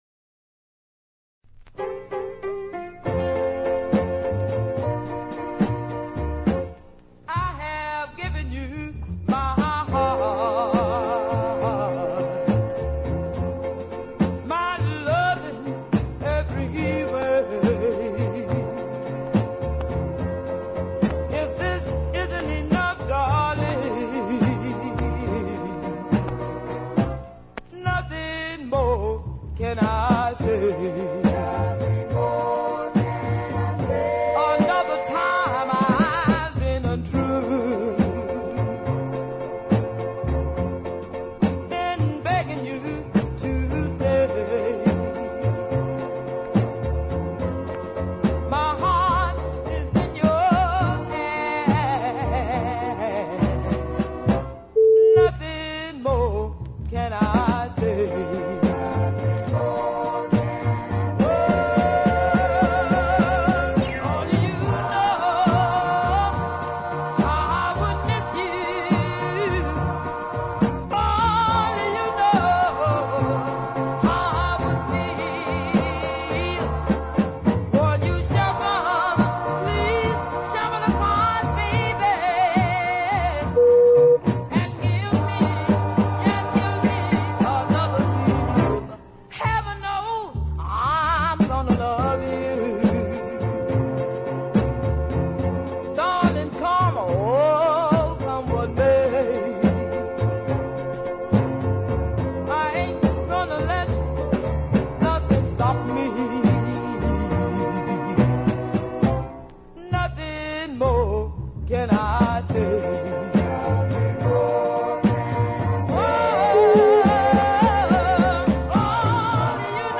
a sound somewhere between doo wop, R & B and soul
Two very nice early soul pieces.